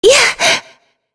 Shea-Vox_Attack4.wav